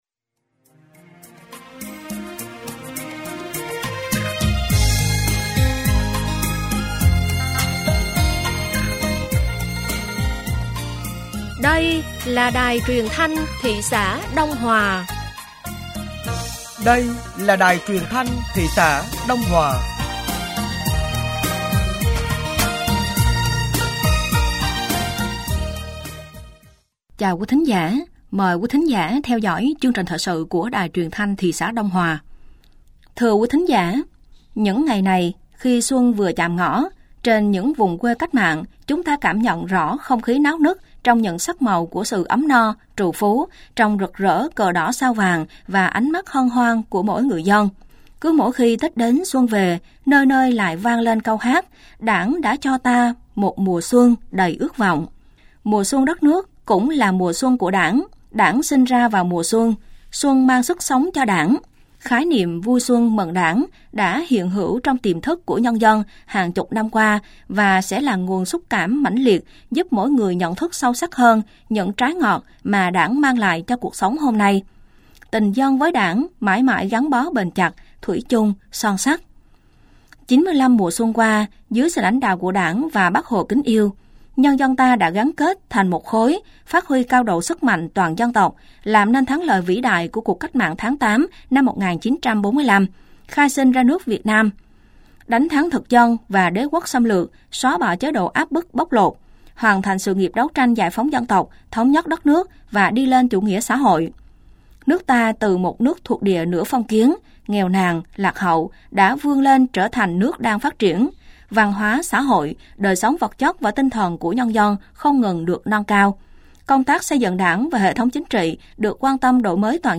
Thời sự tối ngày 01 và sáng ngày 02 tháng 02 năm 2025